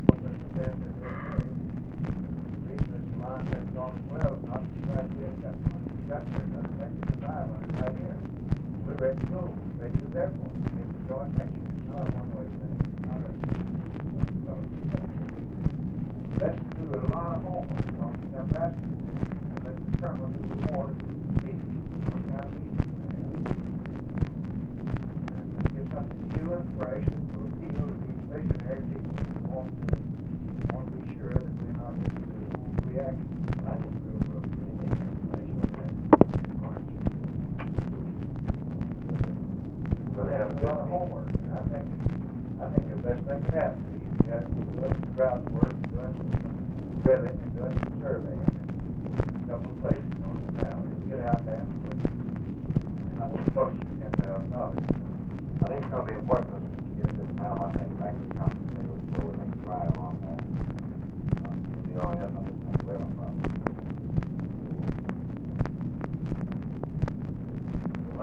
OFFICE CONVERSATION, February 26, 1964
Secret White House Tapes | Lyndon B. Johnson Presidency